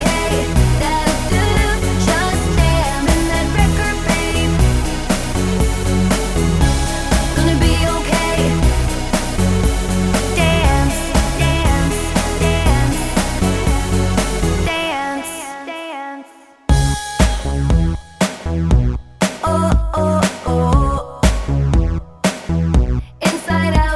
Duet Dance 3:59 Buy £1.50